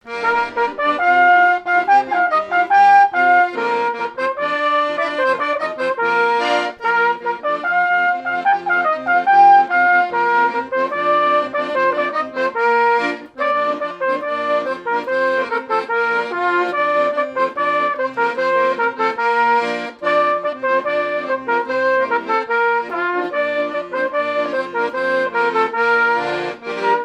airs de danses issus de groupes folkloriques locaux
Pièce musicale inédite